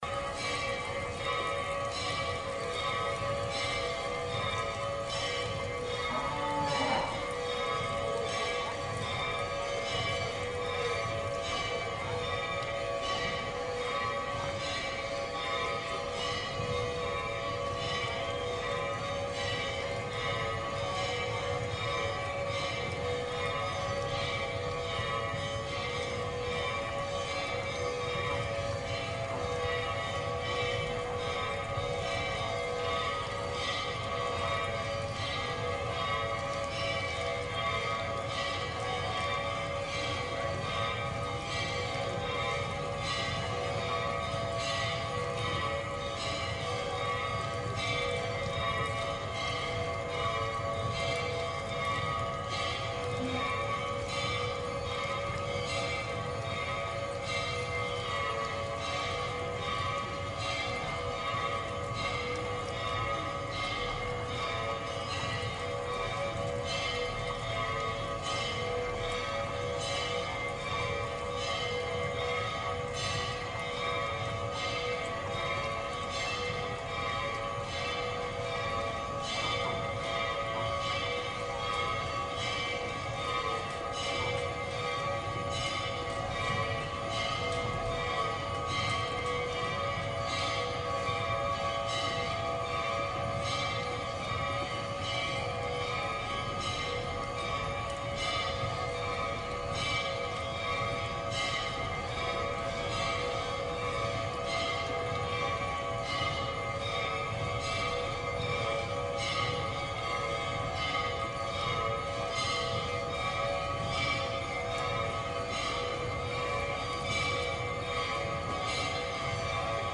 描述：锣在乌兰巴托的一座寺庙里响起。
声道立体声